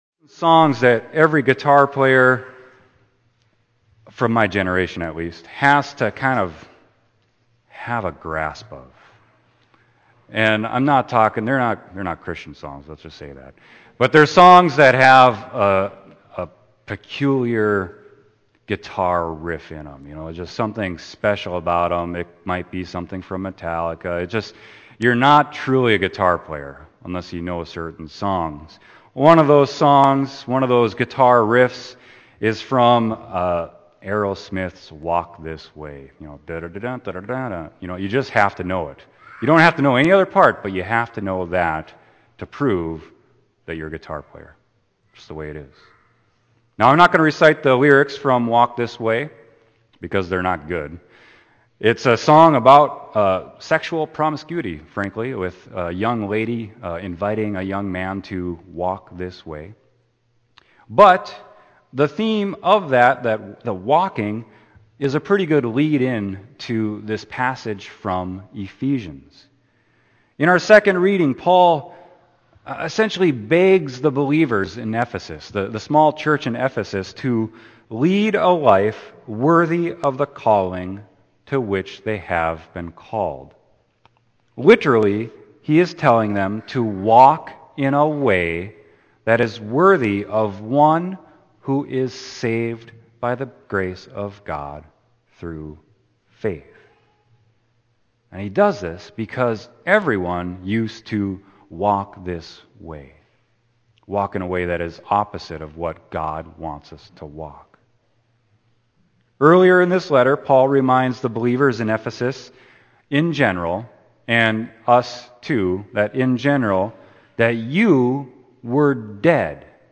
Sermon: Ephesians 4.1-16